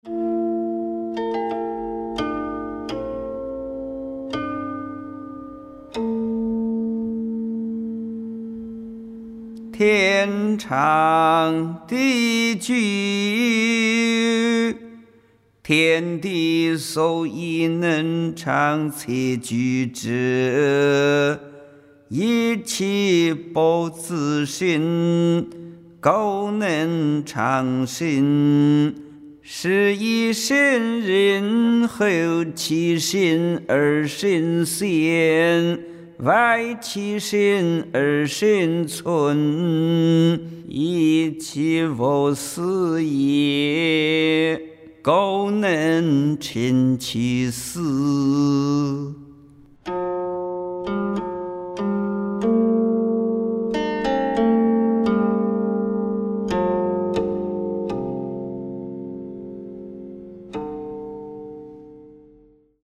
誦唸